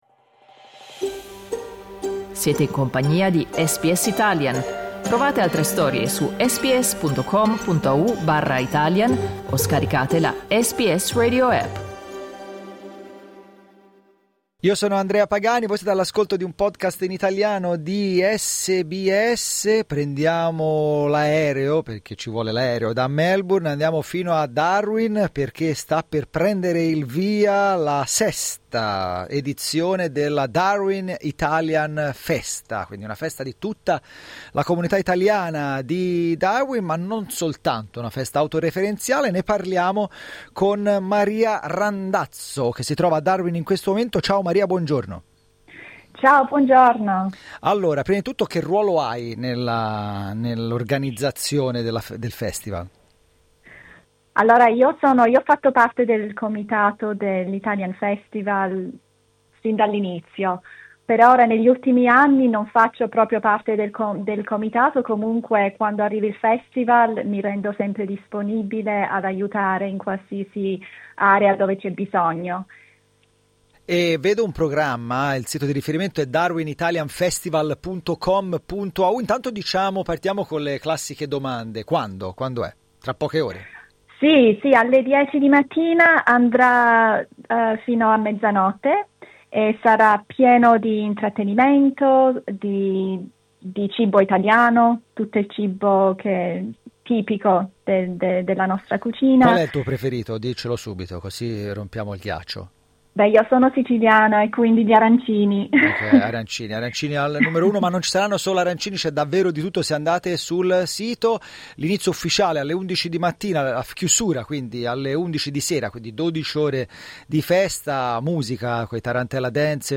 Ascolta l'intervista cliccando sul tasto "play" in alto a sinistra Programma fitto di eventi, tra musica, arte, canto, cibo e attività per i più piccoli.